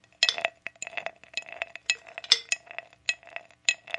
冰块 " 水 冰块 玻璃杯 冰箱制冰机没冰了
描述：冰箱用尽冰，同时填充玻璃杯。 用Tascam DR40录制。
标签： 厨房 冰箱 冰-cube 玻璃杯 玻璃杯 电冰箱 冰块
声道立体声